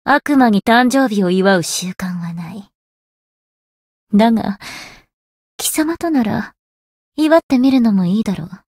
灵魂潮汐-萨缇娅-人偶生日（相伴语音）.ogg